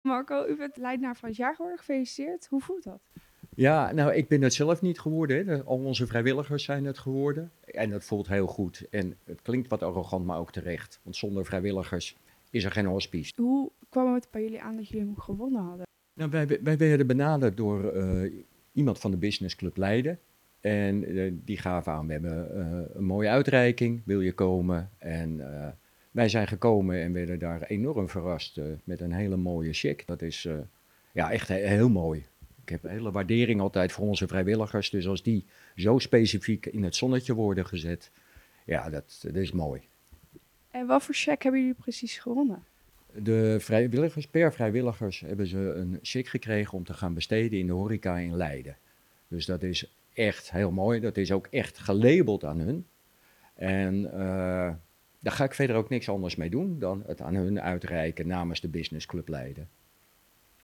Verslaggever
in gesprek met